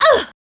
Ripine-Vox_Damage_02.wav